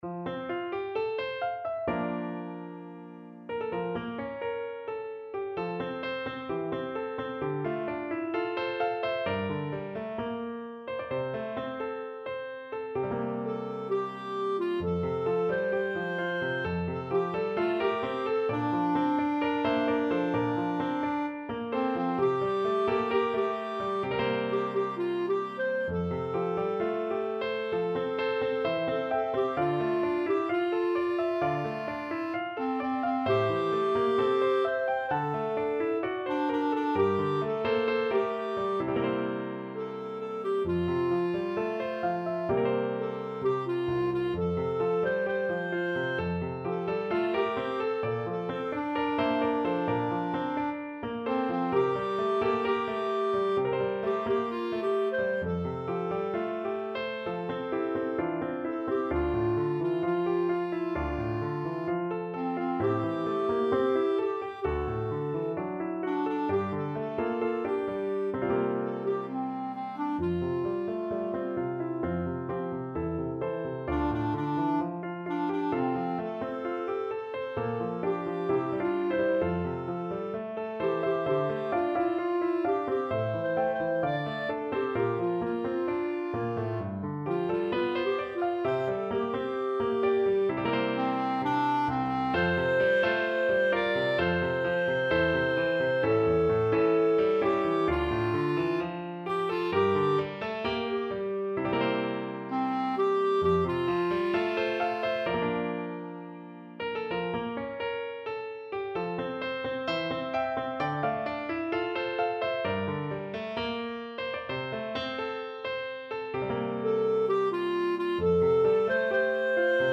Solo voice and piano
世俗音樂
在樂曲方面，整體定調在一個具旋律性較流行的風格，在和聲上也較無困難的音程。